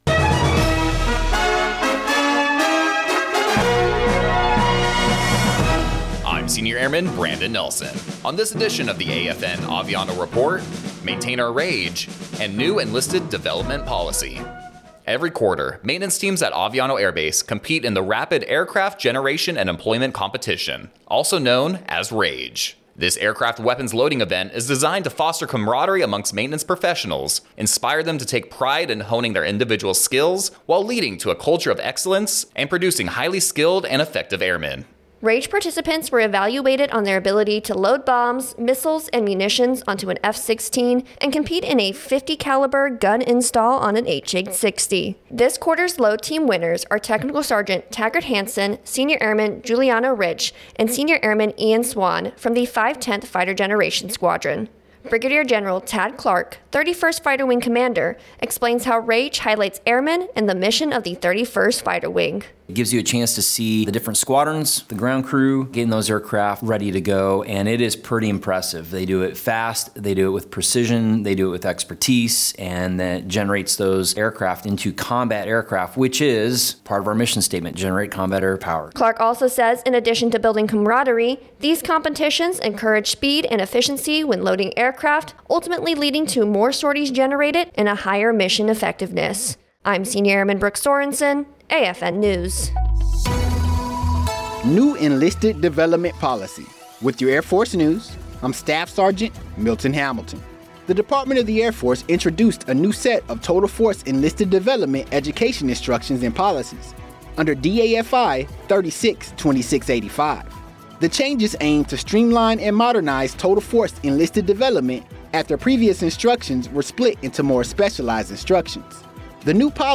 American Forces Network (AFN) Aviano radio news reports on Aviano Air Base’s quarterly Rapid Aircraft Generation and Employment Competition. RAGE is designed to foster camaraderie amongst maintenance professionals, inspiring them to take pride in honing their individual skills, while leading to a culture of excellence, and producing highly skilled and effective Airmen.